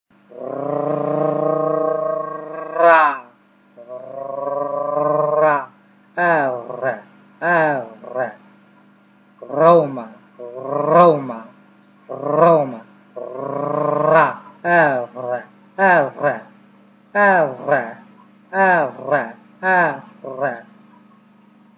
4天苦练R音、成效是这样的大家帮我看看对吗？